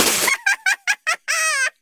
nene_laugh.mp3